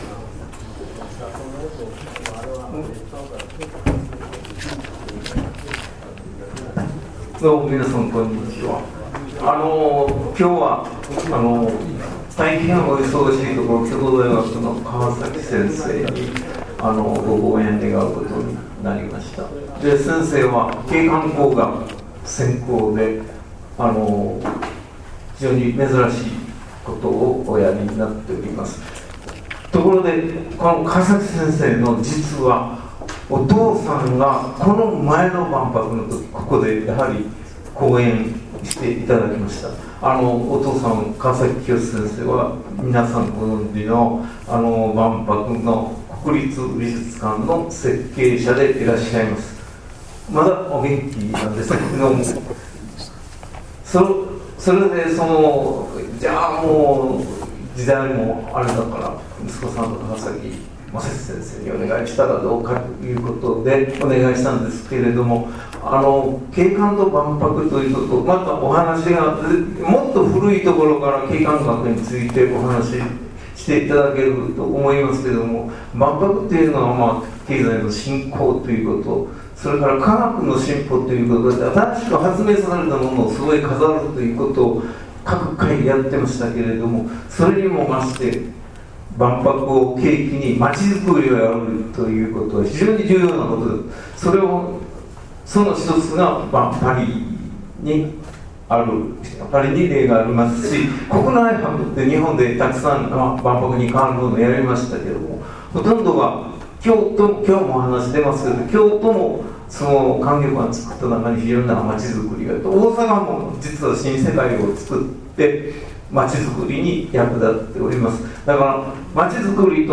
講演会「景観と町づくり」
場所／吹田市立博物館　２階　講座室